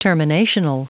Prononciation du mot terminational en anglais (fichier audio)
Prononciation du mot : terminational